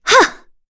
Yuria-Vox_Attack1_kr.wav